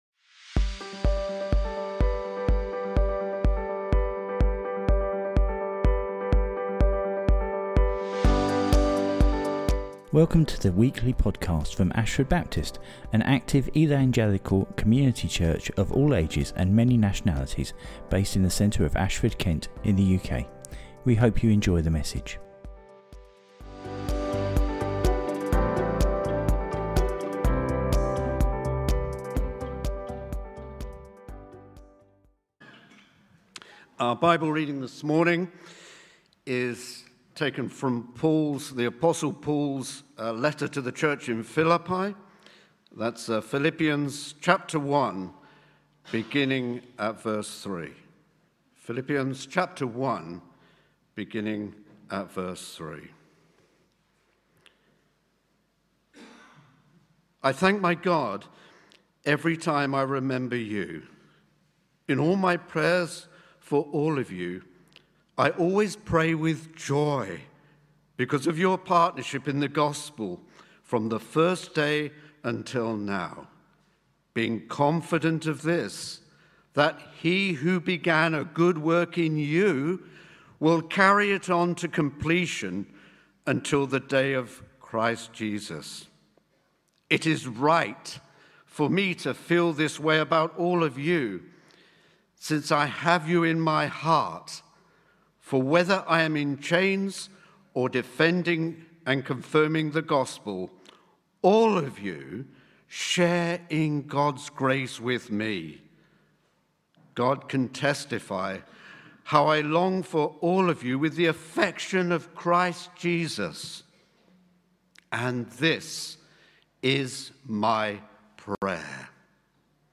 Welcome to the weekly podcast from Ashford Baptist, an active, evangelical, co mmunity church of all ages and many nationalities, based in the centre of Ashford Kent in the UK.